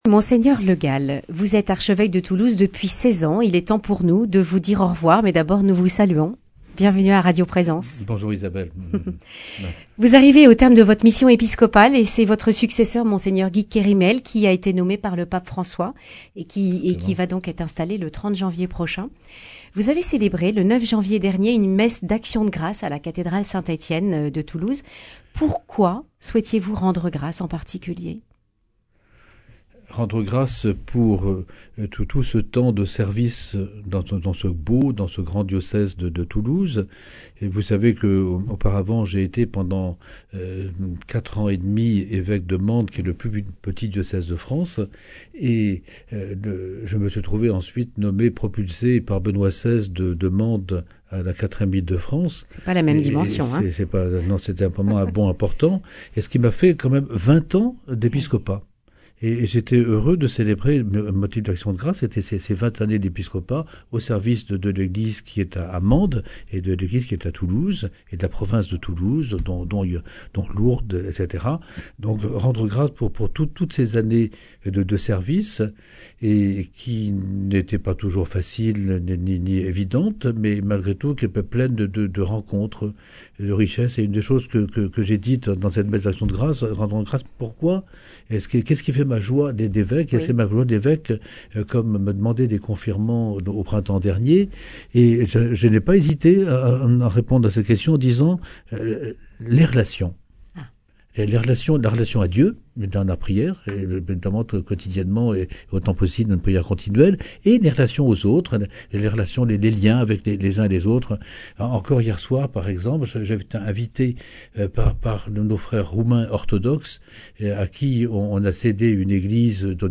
Ayant remis sa charge d’archevêque de Toulouse au pape François pour limite d’âge, Monseigneur Le Gall quitte la région et nous a fait l’amitié de passer une dernière fois dans les studios de radio Présence. Il partage les joies et les difficultés de sa charge d’évêque, invite à l’unité et à la mission.